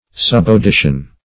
Search Result for " subaudition" : The Collaborative International Dictionary of English v.0.48: Subaudition \Sub`au*di"tion\, n. [L. subauditio.]